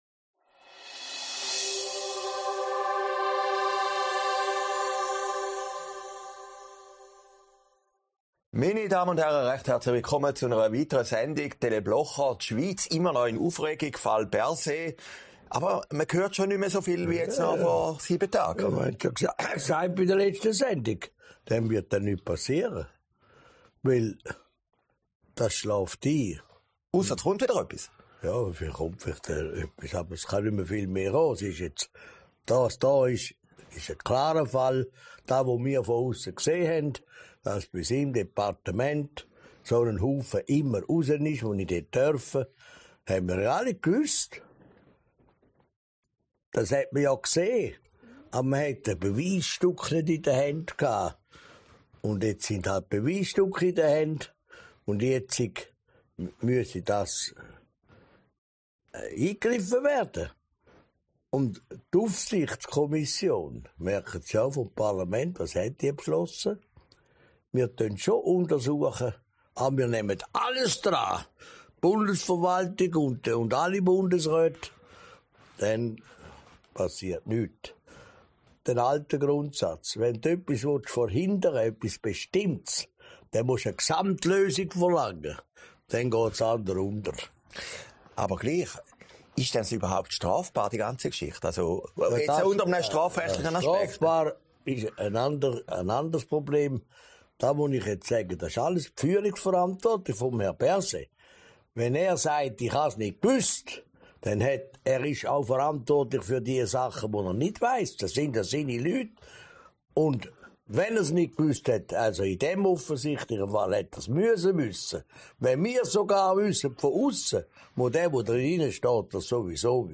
Februar 2023, aufgezeichnet in Herrliberg